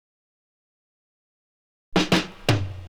Fill.wav